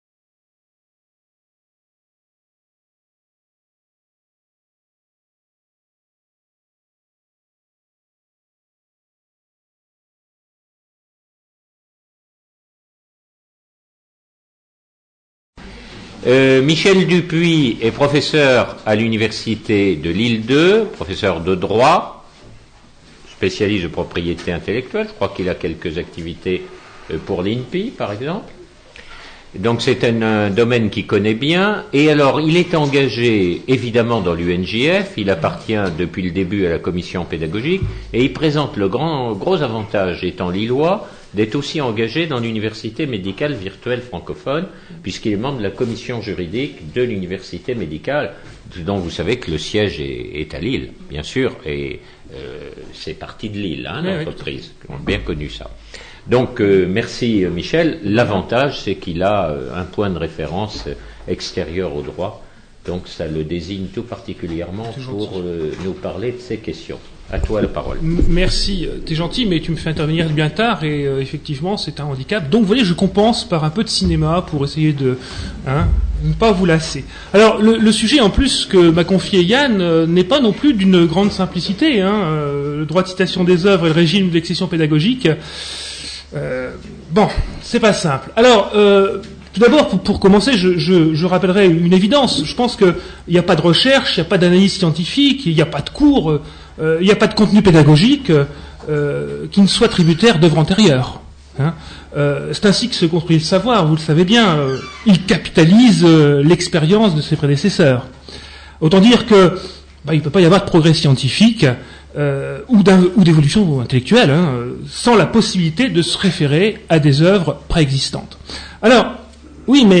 Table ronde
Questions de la salle et réponses des intervenants